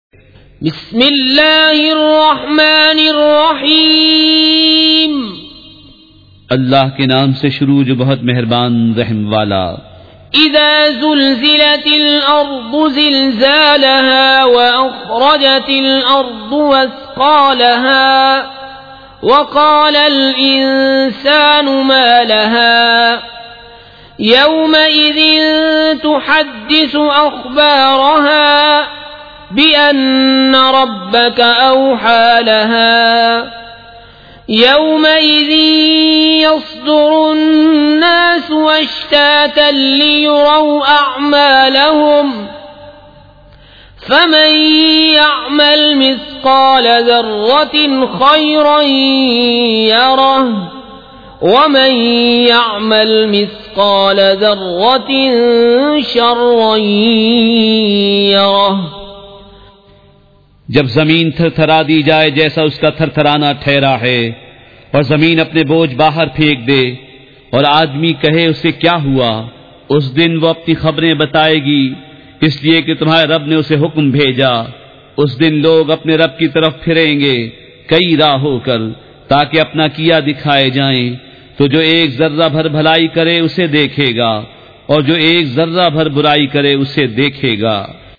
سورۃ الزلزال مع ترجمۃ کنزالایمان ZiaeTaiba Audio میڈیا کی معلومات نام سورۃ الزلزال مع ترجمۃ کنزالایمان موضوع تلاوت آواز دیگر زبان عربی کل نتائج 1928 قسم آڈیو ڈاؤن لوڈ MP 3 ڈاؤن لوڈ MP 4 متعلقہ تجویزوآراء